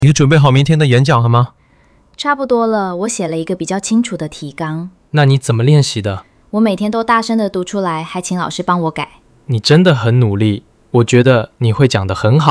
hoi-thoai-phan-biet-3-tro-tu-de.mp3